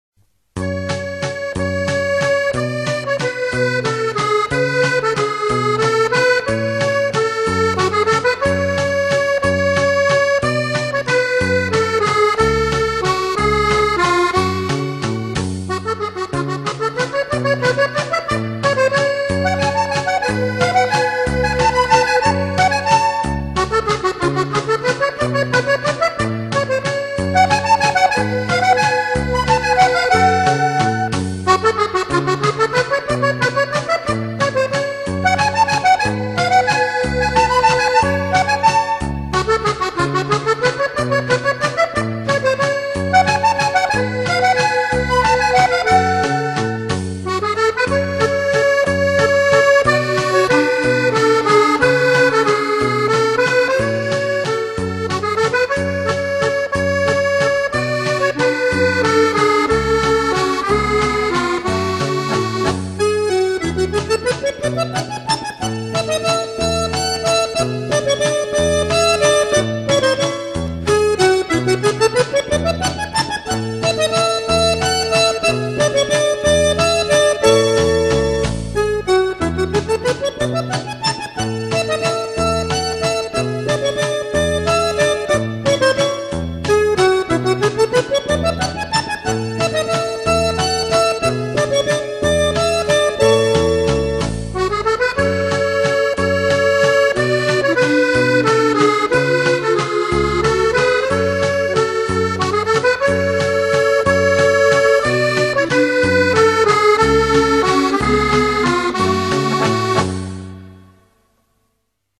Красивый вальс